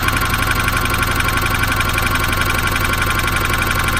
Diesel Engine Idle
A heavy diesel engine idling with rhythmic combustion knock and turbo whistle
diesel-engine-idle.mp3